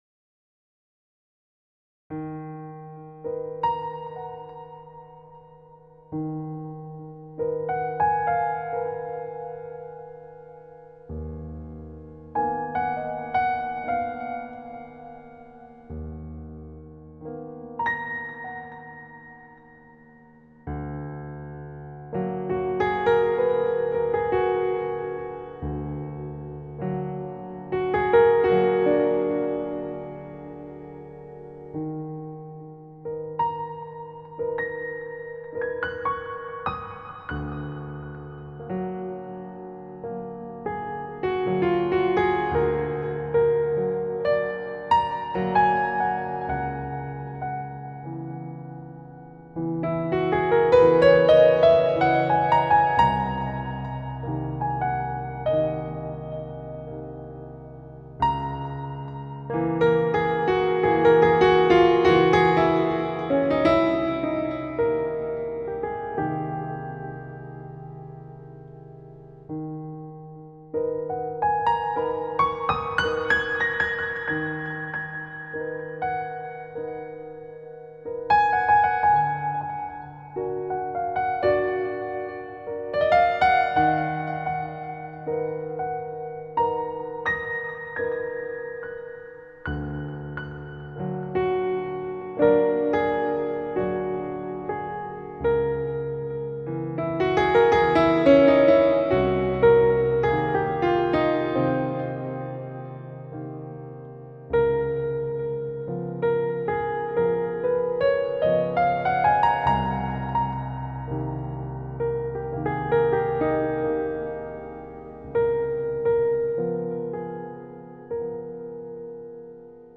eine Klavierimprovisation